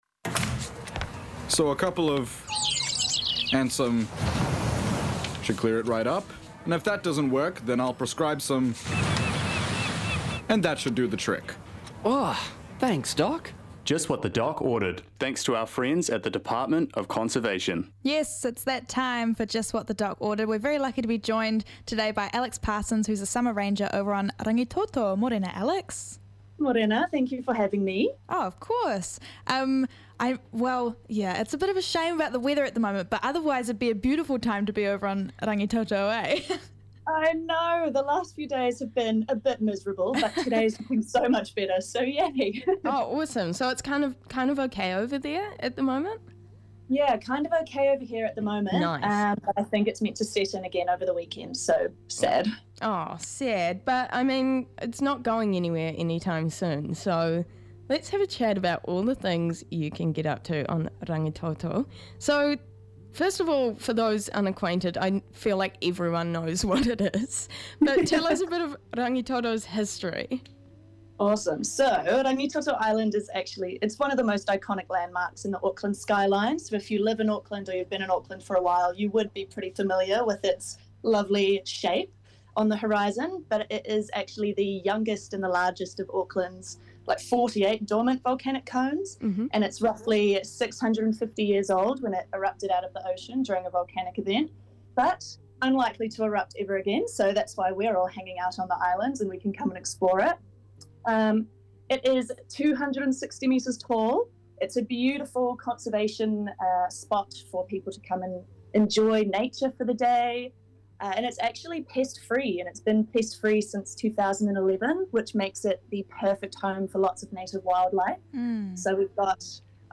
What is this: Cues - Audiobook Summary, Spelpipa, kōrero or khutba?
kōrero